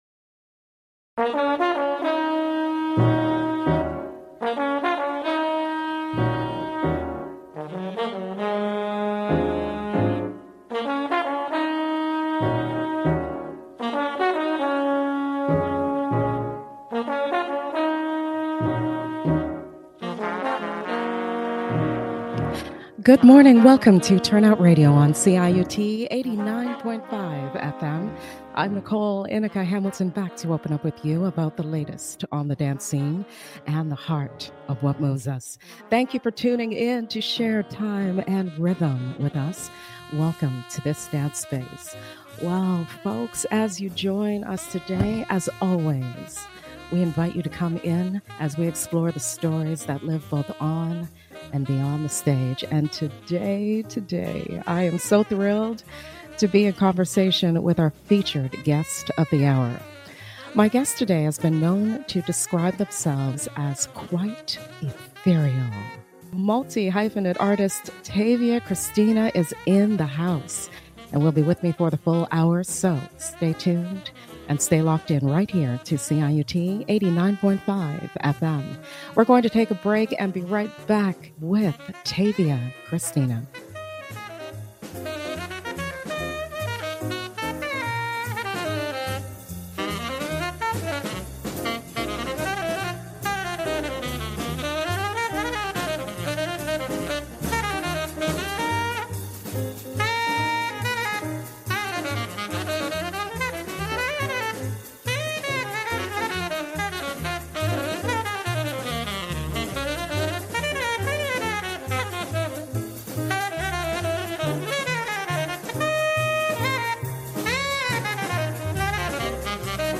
ive on CIUT 89.5 FM